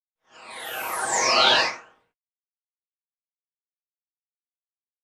Synth Spaceship Approach, In And By 1.